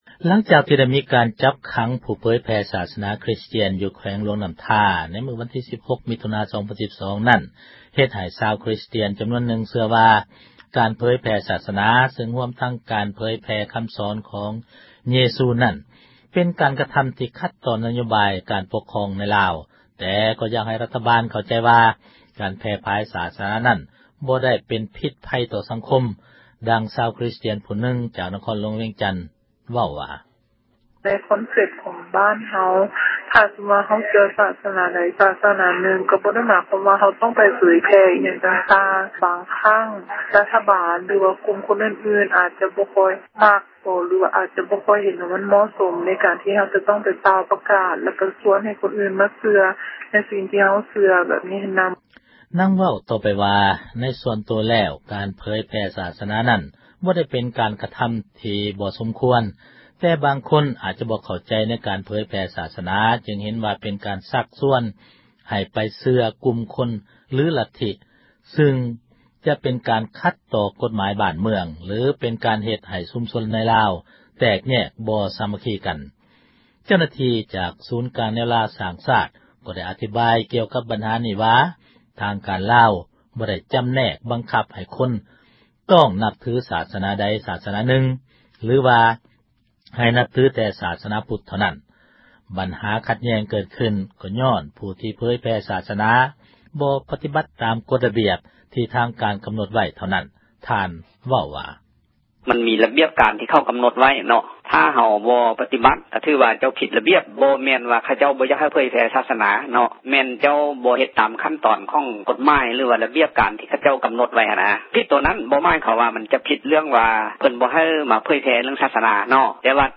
ຫລັງຈາກ ທີ່ໄດ້ມີ ການຈັບຂັງ ຜູ້ເຜີຍແຜ່ ສາສນາ ຄຣິສຕຽນ ຢູ່ແຂວງຫລວງນໍ້າທາ ໃນມື້ ວັນທີ 16 ມິຖຸນາ 2012 ນັ້ນເຮັດໃຫ້ ຊາວຄຣິສຕຽນ ຈໍານວນນຶ່ງ ເຊື່ອວ່າ ການເຜີຍແພ່ ສາສນາ ຊຶ່ງຮວມທັງ ການເຜີຍແພ່ ຄໍາສອນ ຂອງເຍຊູນັ້ນ ເປັນການກະທໍາ ທີ່ຂັດຕໍ່ ນະໂຍບາຍ ການປົກຄອງ ໃນລາວ ແຕ່ກໍຢາກ ໃຫ້ທາງຣັຖບານ ເຂົ້າໃຈວ່າ ການແພ່ຜາຍ ສາສນານັ້ນ ບໍ່ໄດ້ເປັນ ພິດພັຍ ຕໍ່ສັງຄົມ ດັ່ງຊາວຄຣິສຕຽນ ຜູ້ນຶ່ງ ຈາກນະຄອນ ຫລວງວຽງຈັນ ເວົ້າວ່າ: